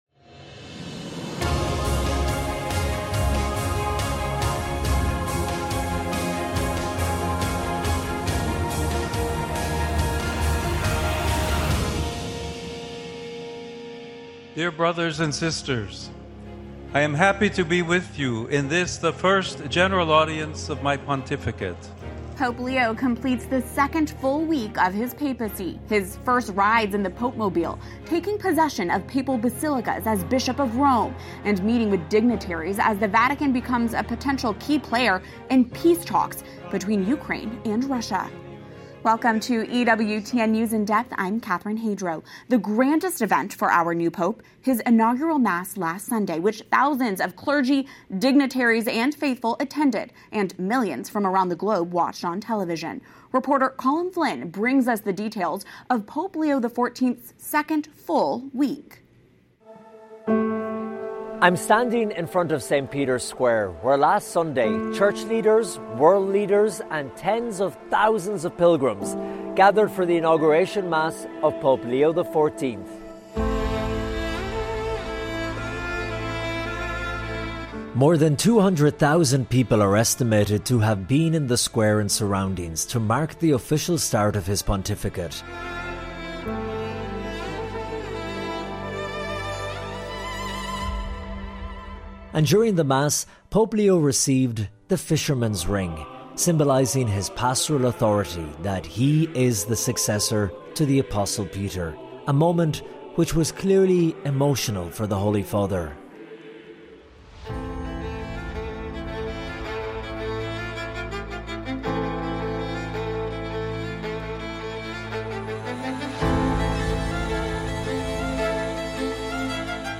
EWTN’s weekly one-hour discussion of current events in the Church, politics, and culture, from a Catholic perspective.